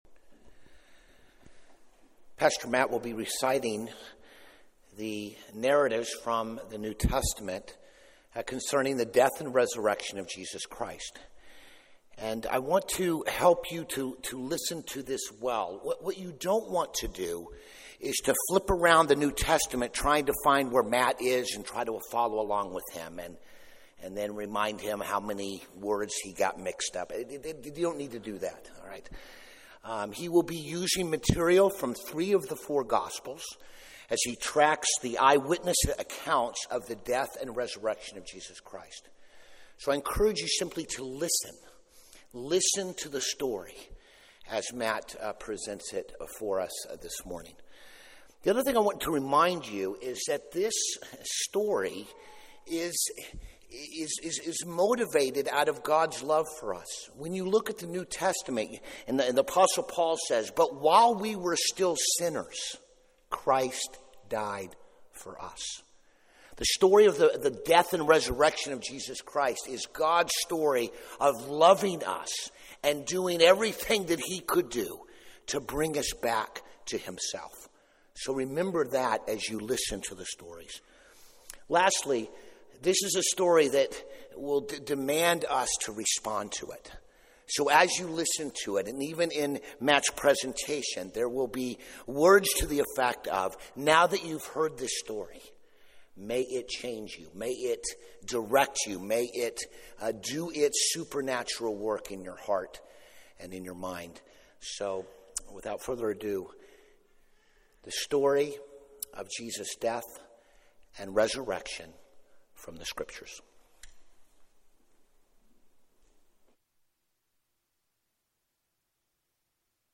Stone Hill Church of Princeton Easter According to the Gospels Apr 21 2019 | 00:26:03 1x 00:00 / 00:26:03 Subscribe Share Apple Podcasts Spotify Overcast RSS Feed Share Link Embed
4-21-sermon.mp3